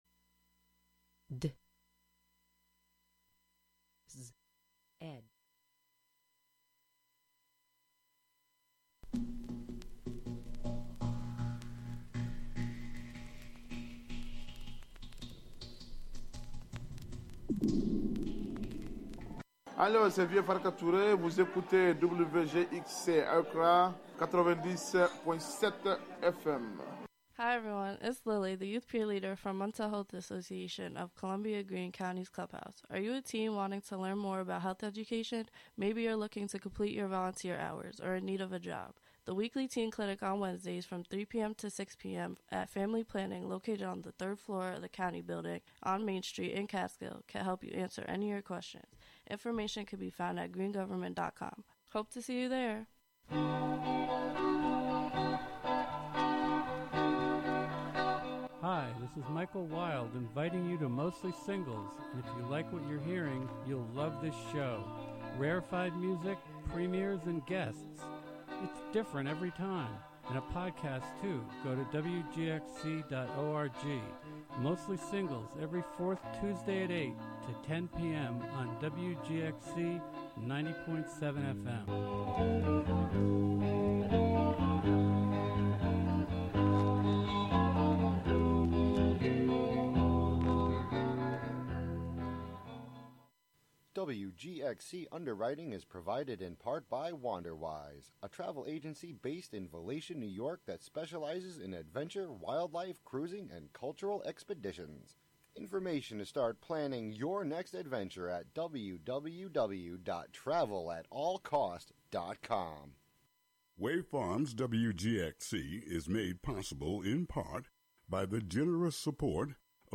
stop by the studio to talk music, small business, and the joys and challenges of parenthood.